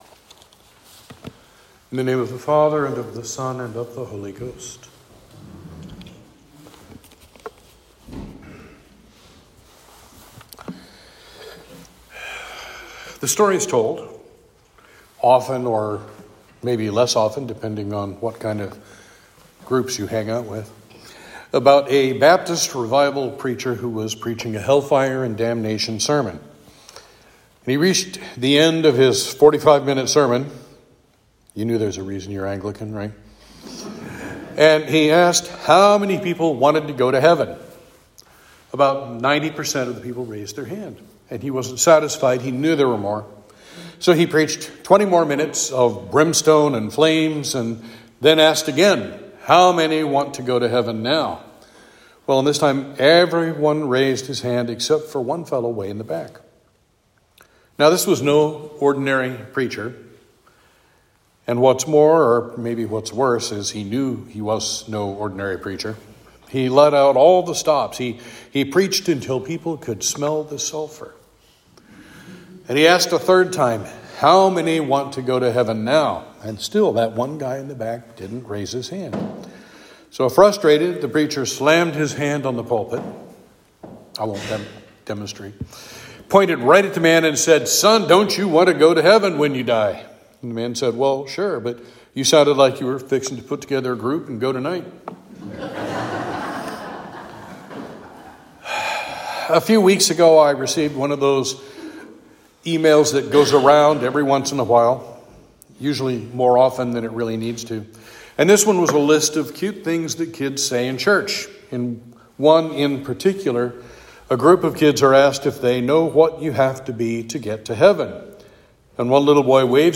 Sermon for Trinity 24